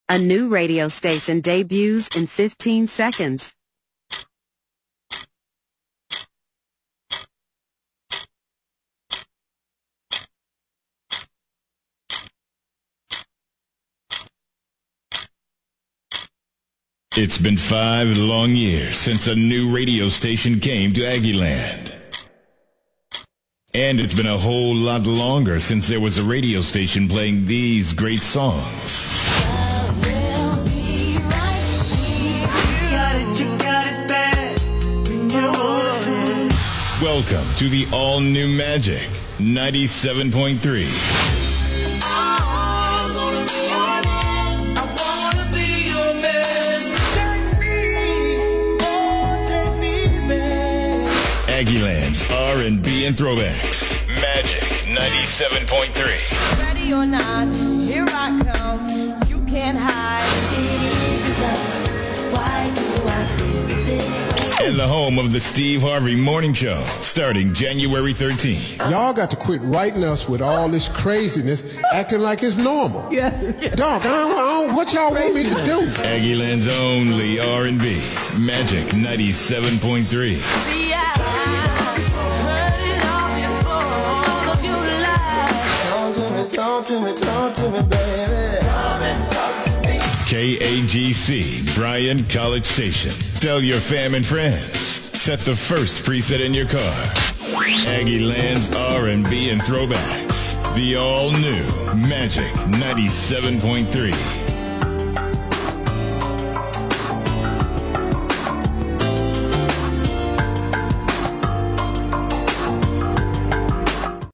Bryan Broadcasting is proud to announce the debut of the newest local radio station serving our community, “Magic 97.3”, becoming the only R&B station in the Brazos Valley.